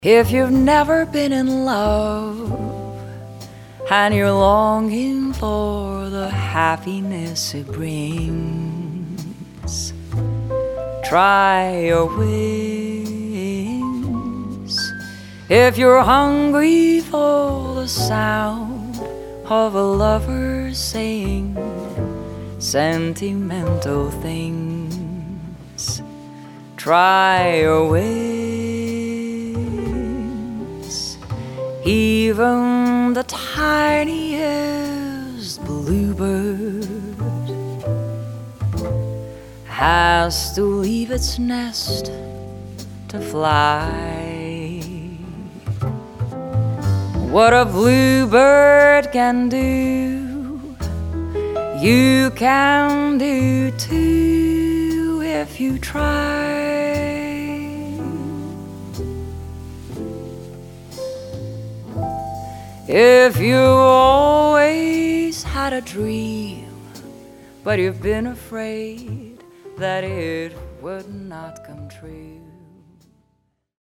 Vocalist
Grand Piano
Double-Bass
Drums
Guitar
Violin
Trombone